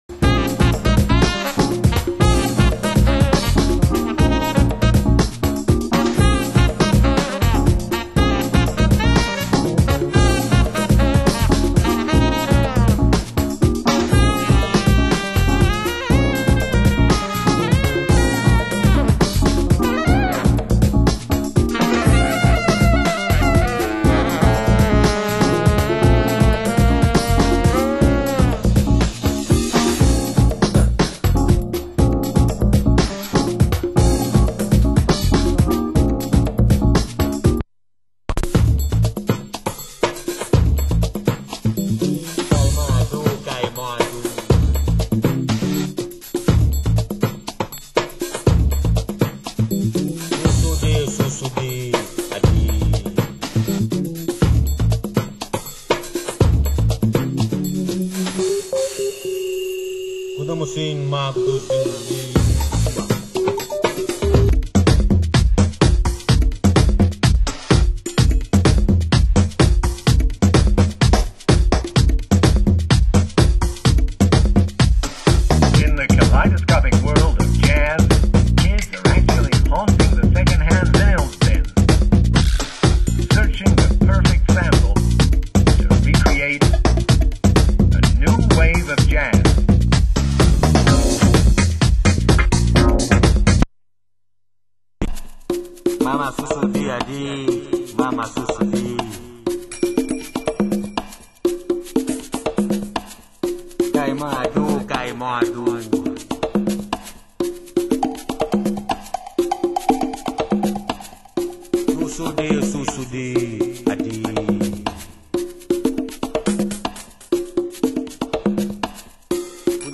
Genre: Funky House